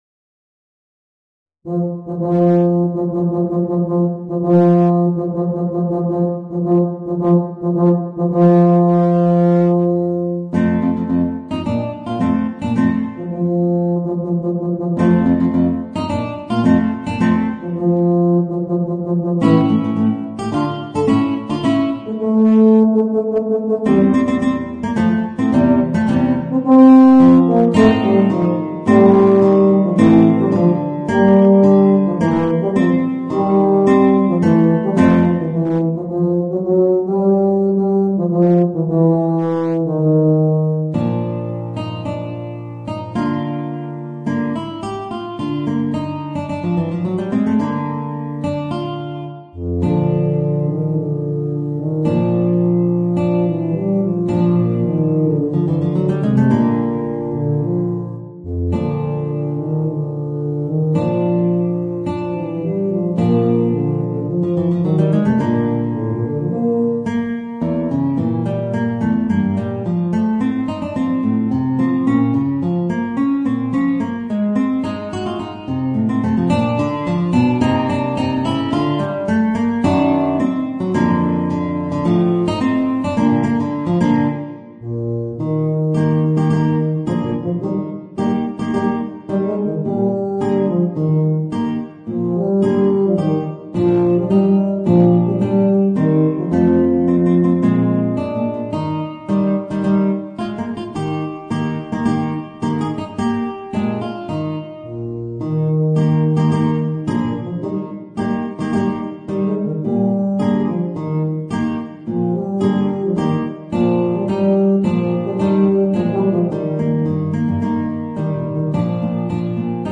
Voicing: Guitar and Eb Bass